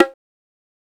MZ Bongo [Dro Bongo #2].wav